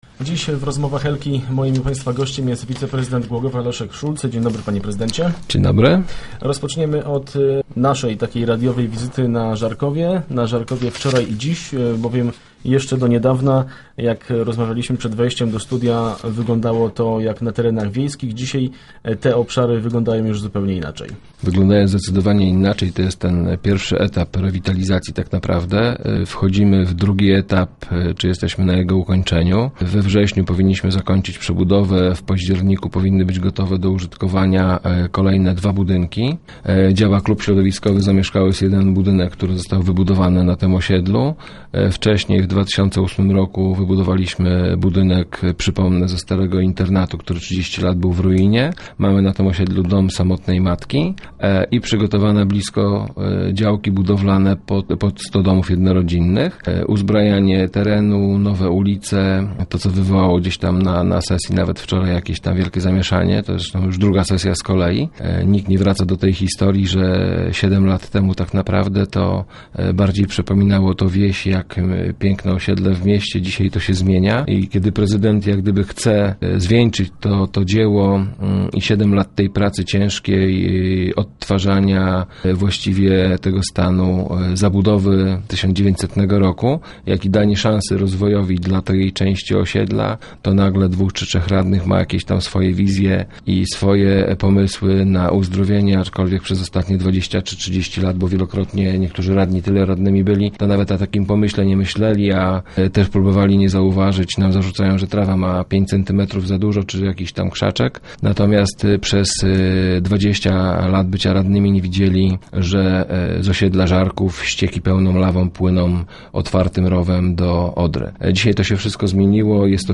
W przyszłym tygodniu ruszy nabór wniosków osób, które będą chciały zamienić swoje lokale i zamieszkać w nowych budynkach na rewitalizowanym  osiedlu. O dawnym i obecnym Żarkowie w rozmowie z wiceprezydentem Głogowa Leszkiem Szulcem.